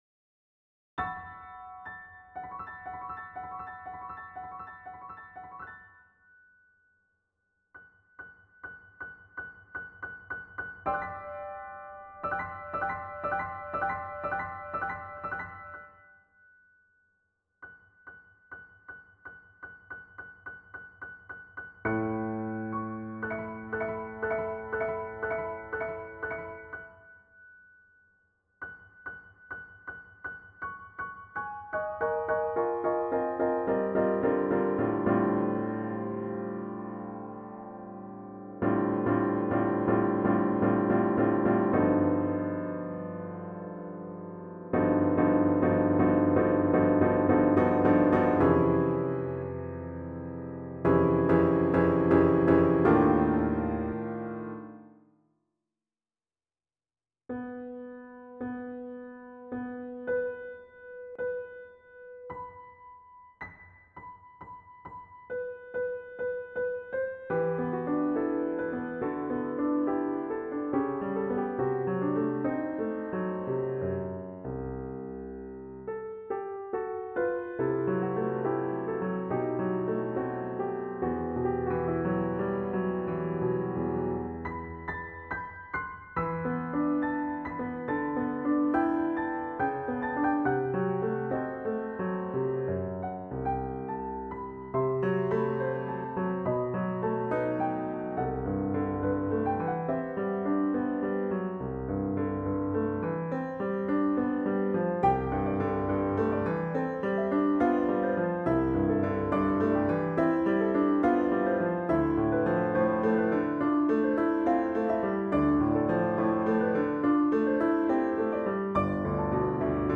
en la categoría de Música clásica/contemporánea